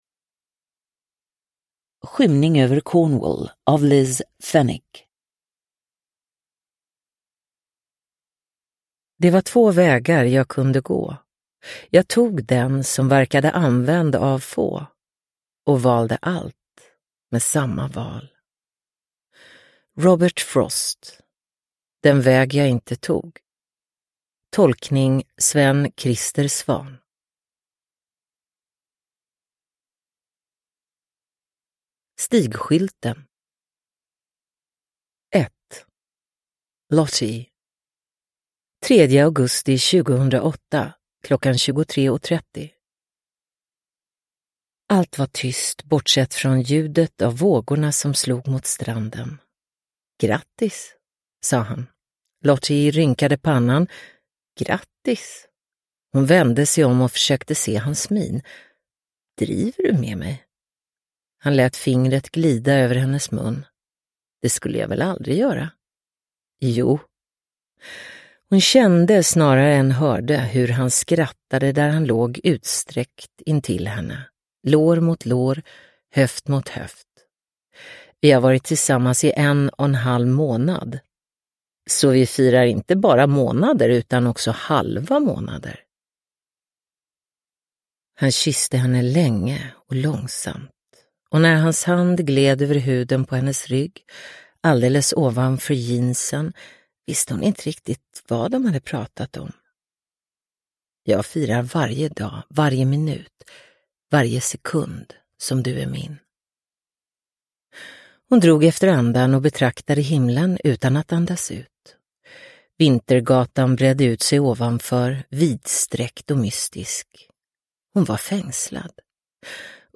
Skymning över Cornwall – Ljudbok – Laddas ner